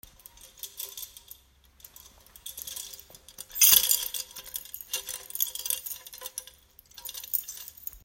Przyporządkujcie numerki do właściwych dźwięków: łamanie hostii, przewracanie stron w Mszale, zamykanie drzwi w kościele, dzwonki, wlewanie wody do kielicha, otwieranie drzwi do konfesjonału, gong, machanie kadzidłem.